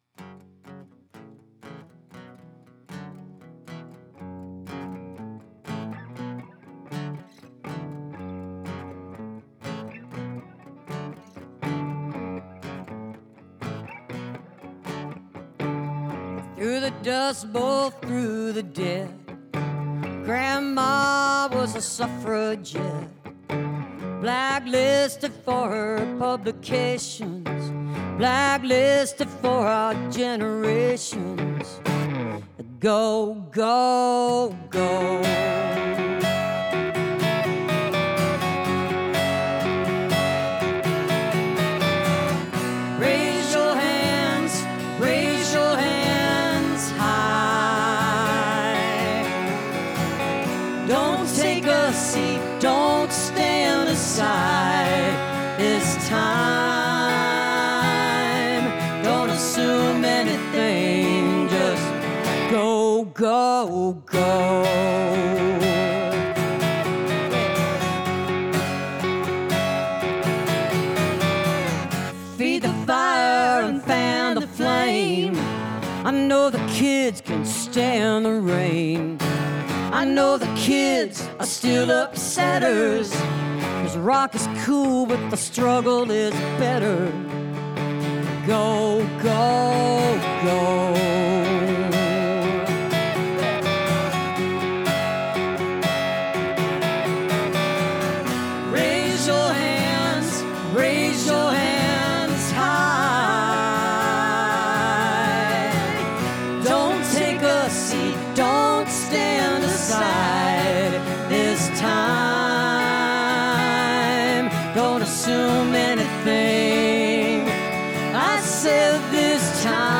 (audio captured from webcast)